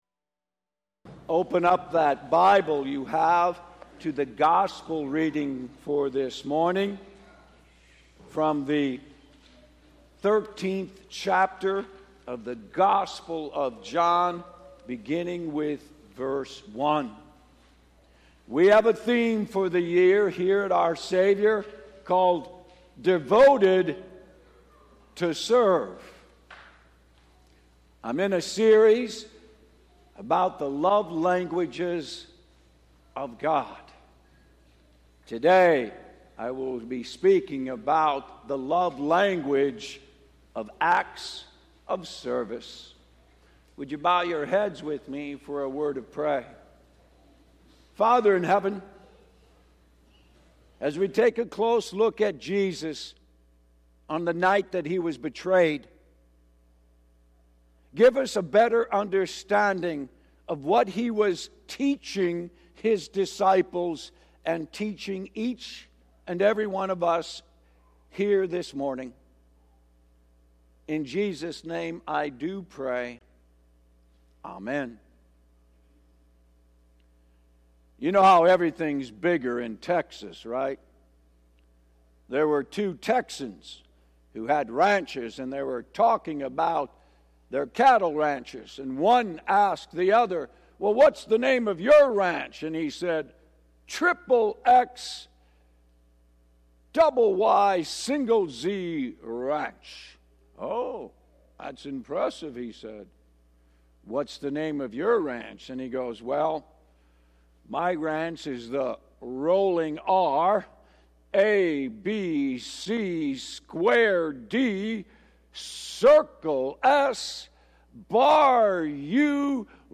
A message from the series "Devoted to Serve."